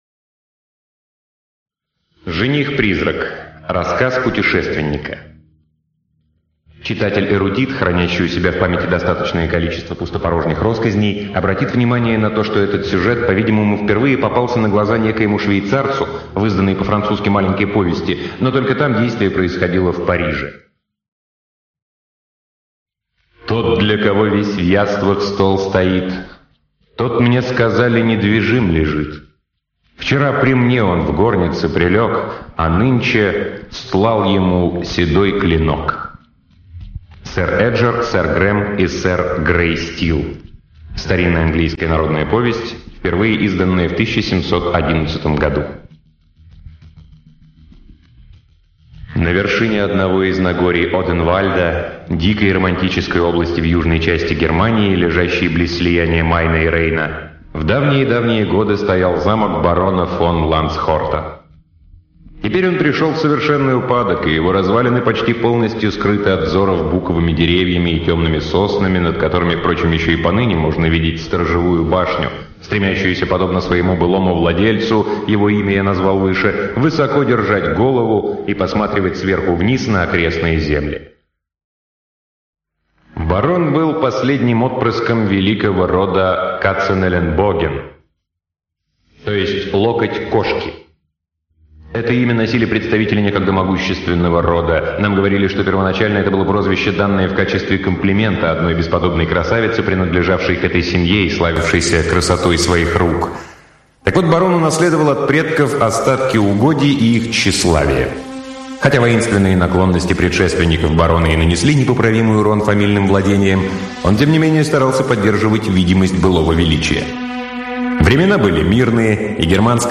Жених-призрак - аудио рассказ Ирвинга - слушать онлайн